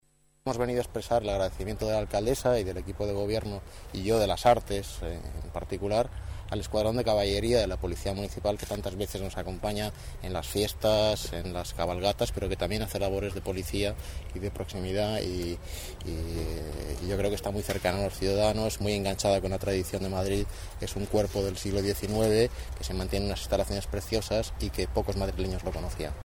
Nueva ventana:Declaraciones del delegado, Fernando Villalonga.